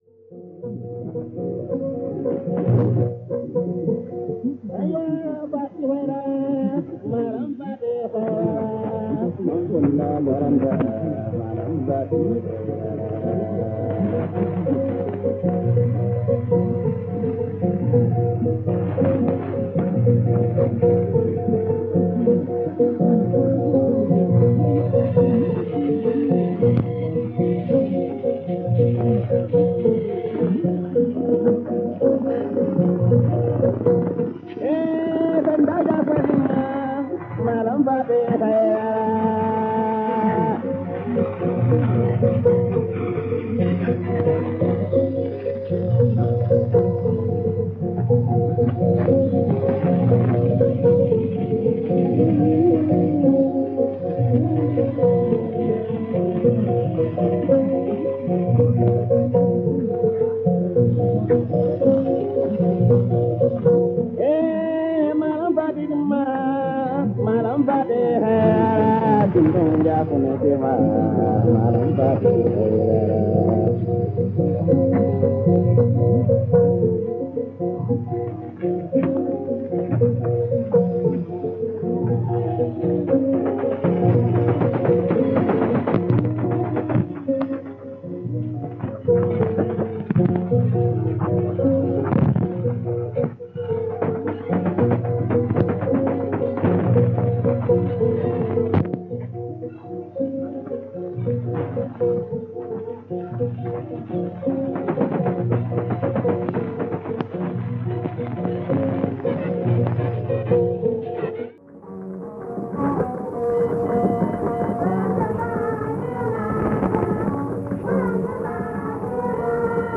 R Dakar / SEN 764 kHz - Empfangsort Schwäbisch Gmünd 1981 - Satellit 2000
Rx, Ant: Grundig Satellit 2000 mit JM Rahmenantenne
Px: Vn, local mx, Griot, TS, F nx, fade out
SINPO: 43333
Bemerkung: Nach Sendeschluß von Sottens/CH immer eine dankbare Quelle für afrikanische Klänge,